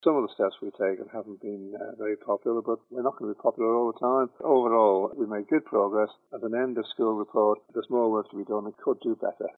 Eddie Teare says overall it's been a good year: